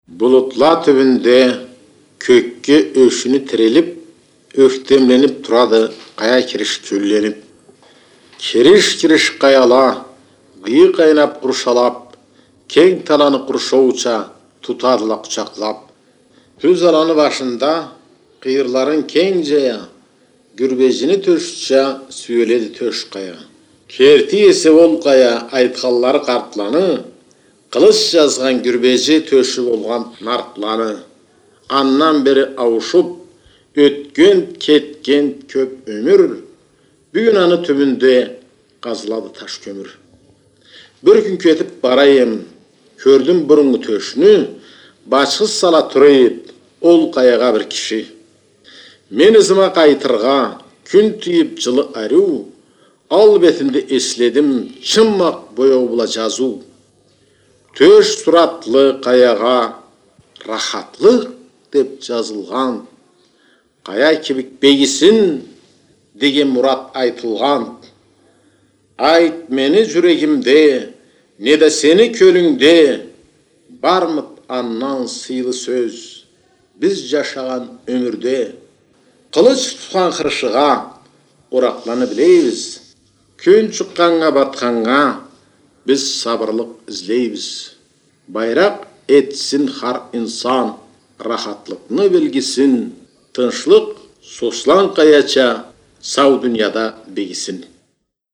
назму